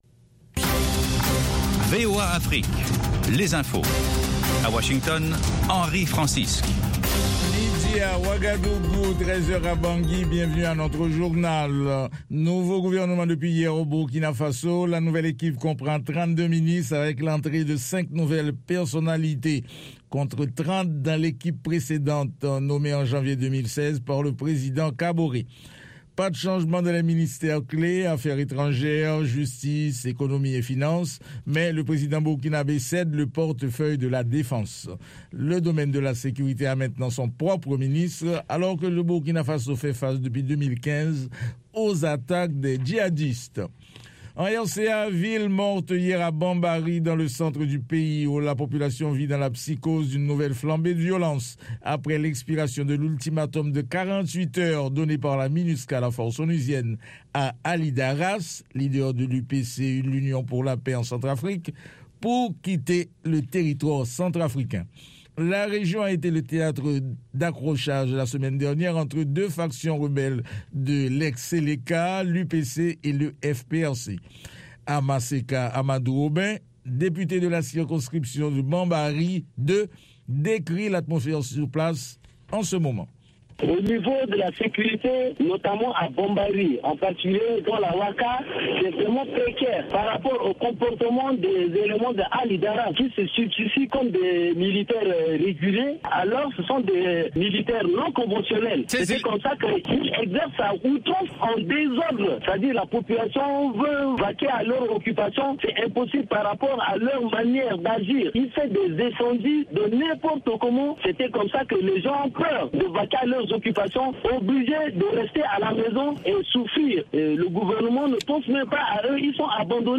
Murisanga itumira umutumirwa, cyangwa abatumirwa kugirango baganira n'abakunzi ba Radiyo Ijwi ry'Amerika. Aha duha ijambo abantu bivufa kuganira n'abatumirwa bacu, batanga ibisobanuro ku bibazo binyuranye bireba ubuzima b'abantu.